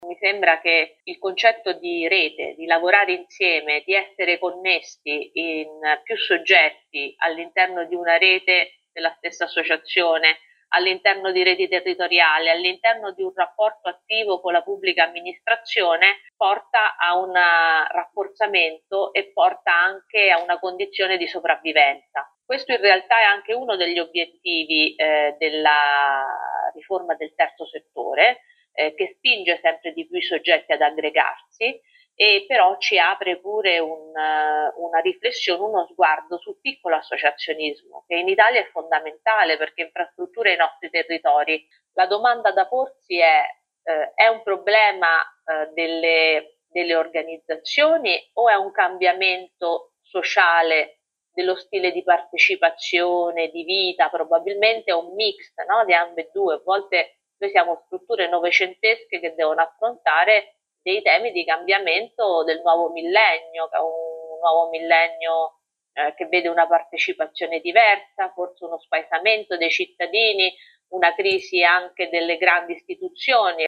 Bentornati all’ascolto del Grs Week.